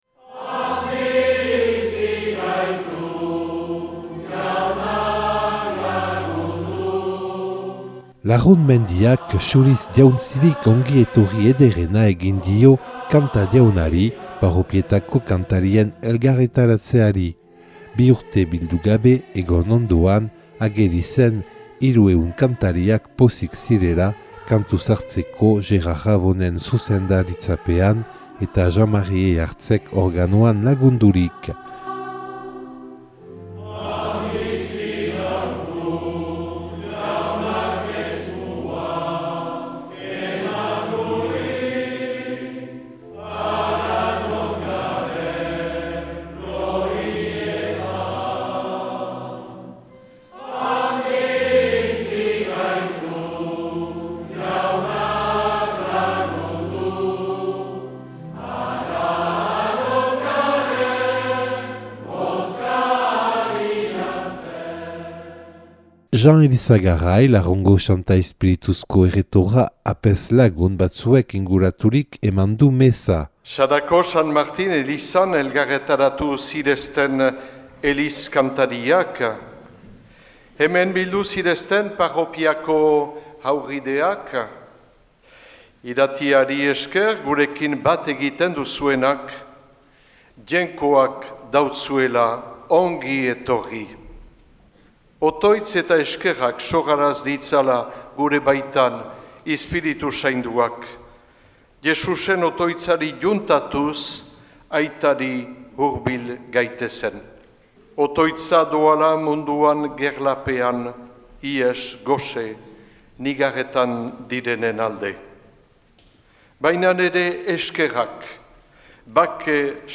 Sarako herriak ongi etorria egin diote Euskal Herriko parropietako koraleri